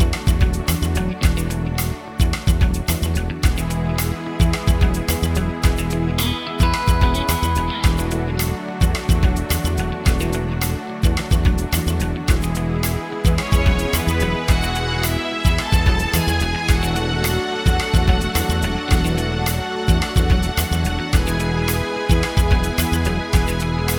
no Backing Vocals Duets 4:50 Buy £1.50